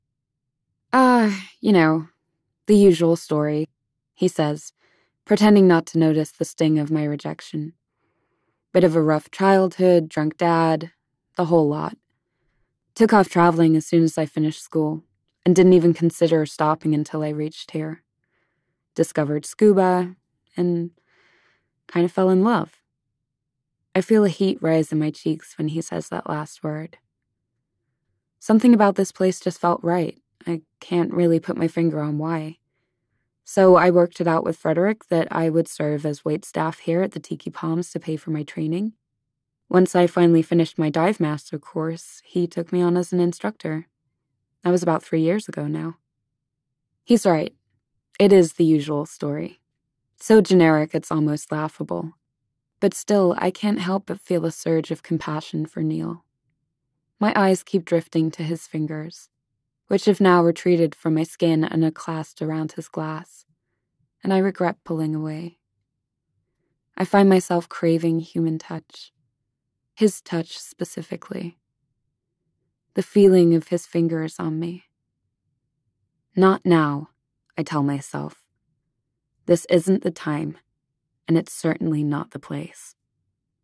20/30's Neutral/RP,
Compelling/Cool/Assured
The Resort by Sara Ochs (US accent) Triple Threat by Mike Lupica (US accent)